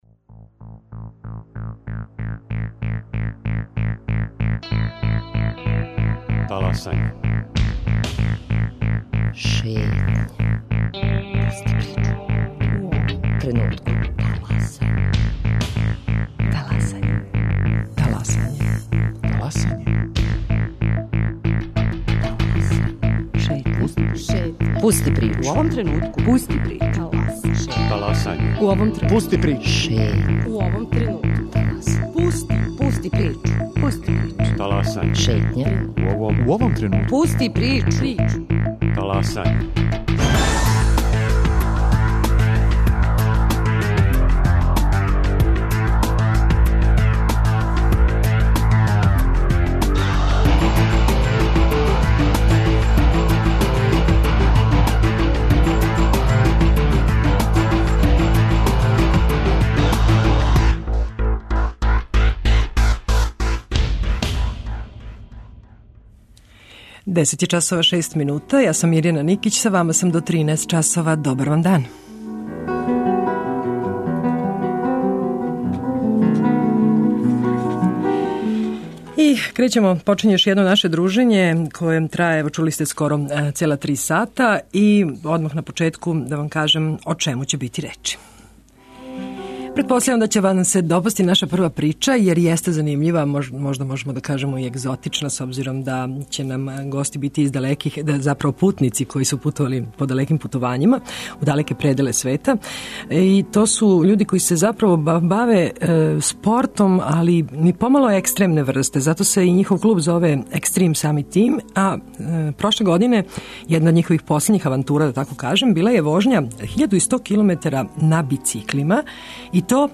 Наши гости, чланови клуба 'Extreme summit team', причаће о нестварној лепоти планина и језера на Тибету, где су током 14 дана прешли пуних 1.100 километара на бициклима.